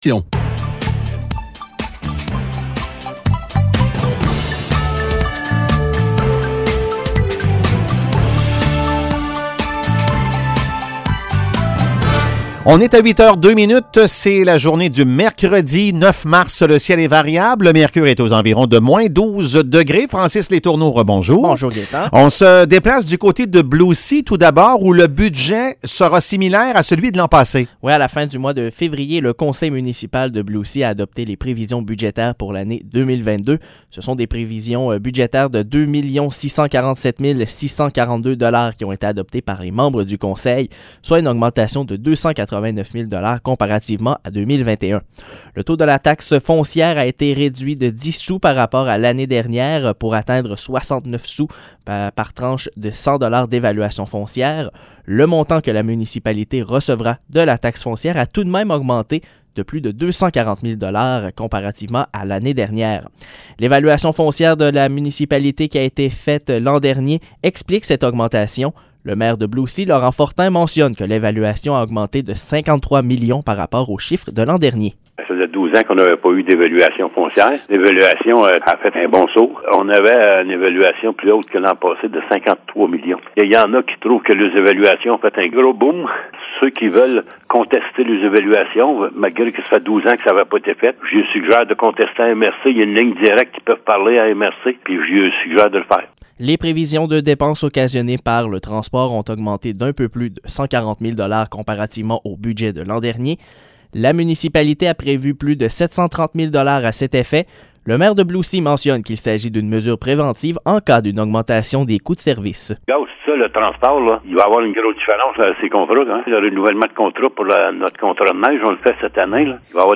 Nouvelles locales - 9 mars 2022 - 8 h